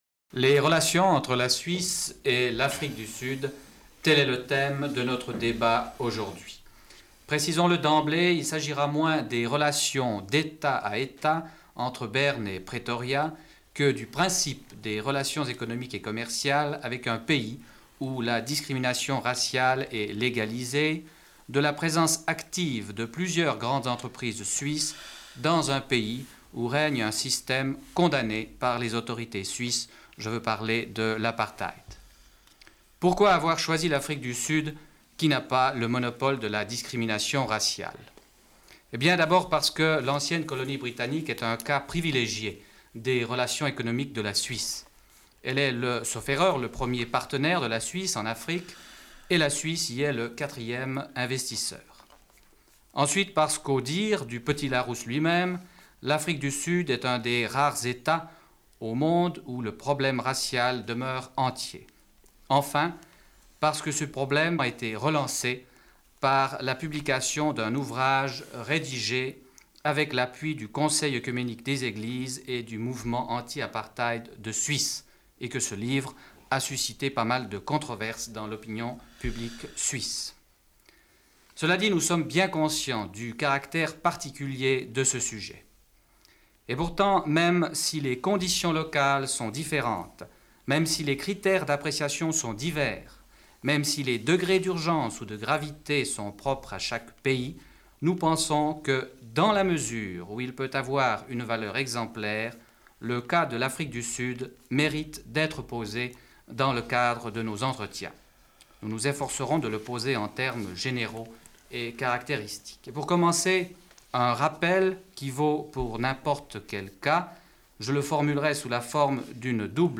Débat sur le principe des relations économiques et commerciales avec l'Afrique du Sud, où la discrimination raciale est légalisée, et de la présence active de plusieurs grandes entreprise suisses dans ce pays où règne un système condamné par les autorités suisses: l'apartheid.